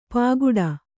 ♪ pāguḍa